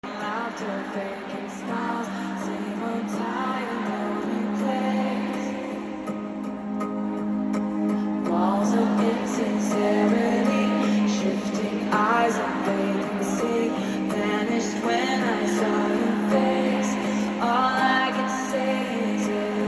Obsessed with how loud the crowd is here